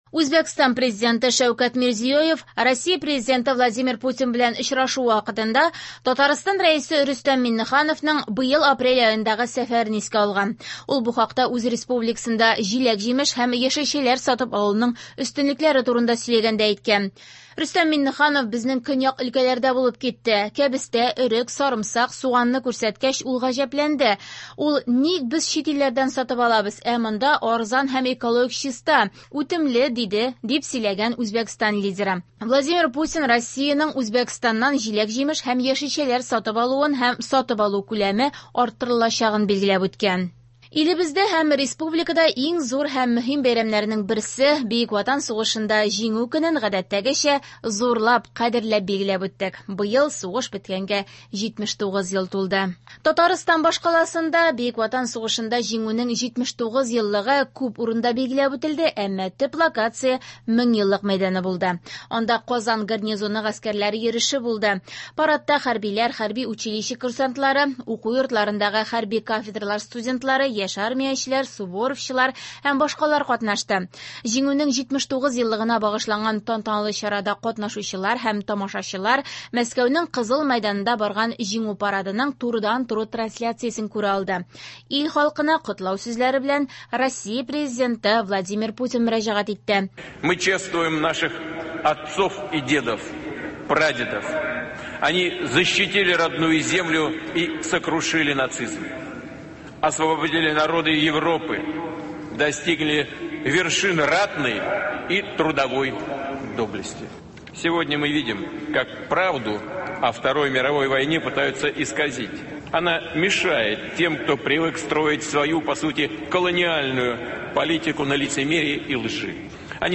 Яңалыклар (10.05.24)